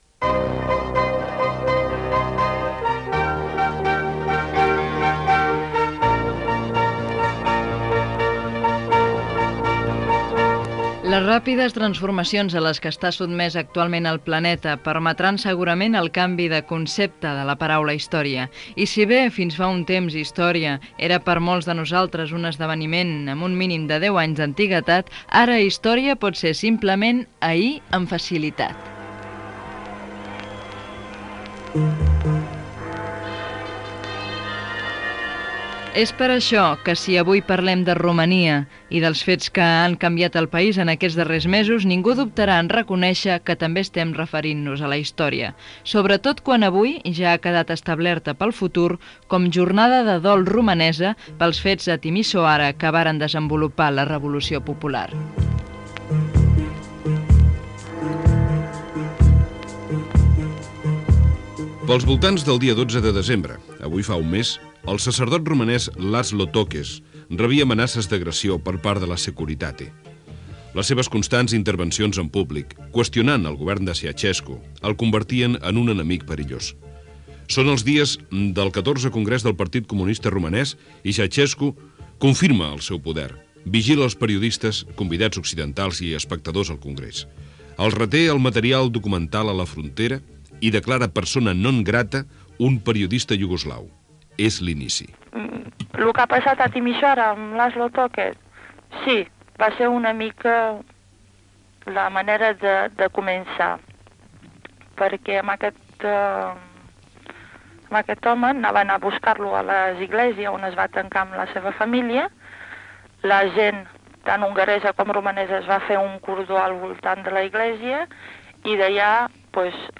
Reportatge sobre els canvis polítics a Romania i la revolució popular que va enderrocar el govern comunista i autoritari de Nicolae Ceauşescu
Informatiu